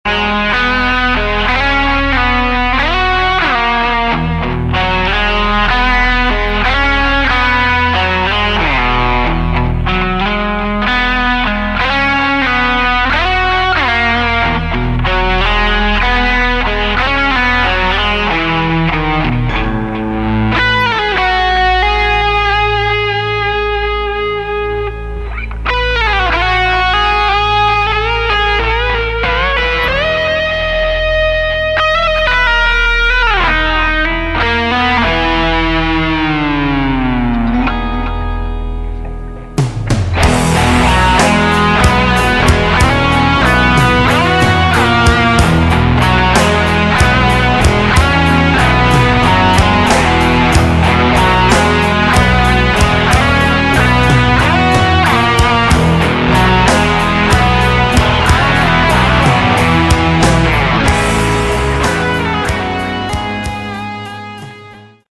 Category: Melodic Rock / AOR
Guitar, Bass, Keyboards, Drums
lead vocals